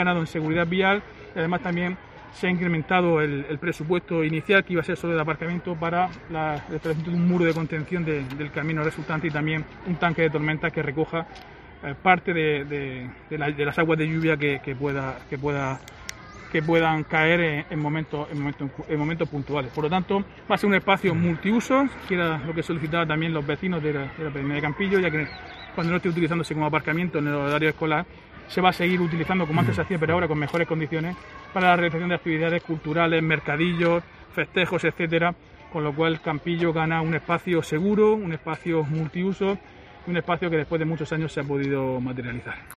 Diego José Mateos, alcalde Lorca sobre parking Campillo